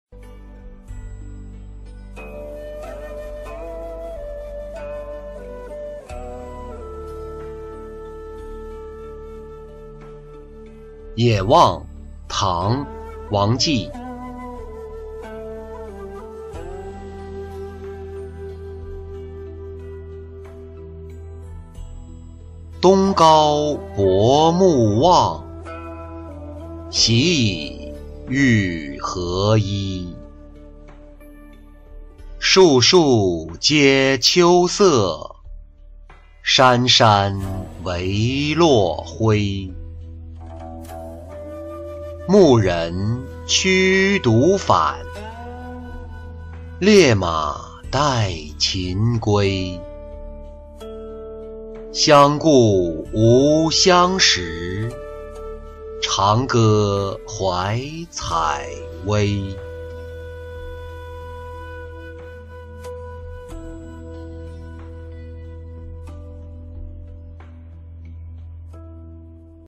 野望-音频朗读